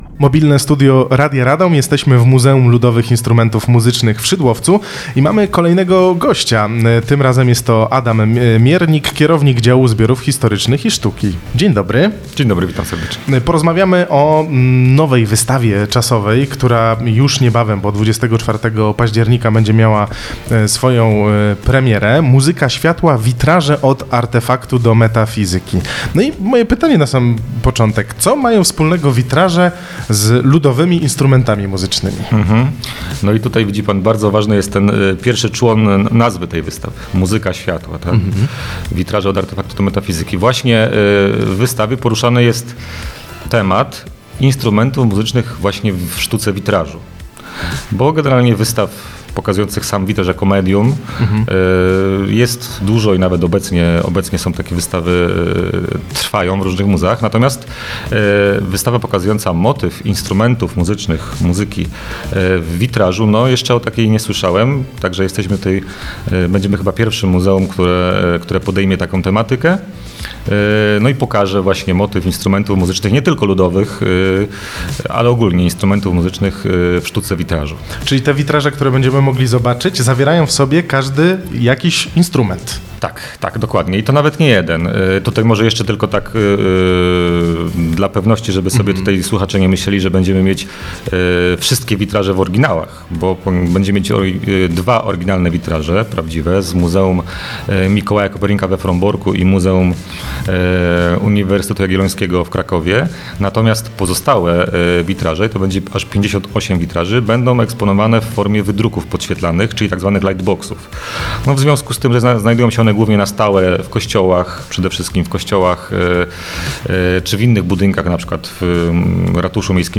Mobilne Studio Radia Radom dzisiaj w Muzeum Ludowych Instrumentów Muzycznych w Szydłowcu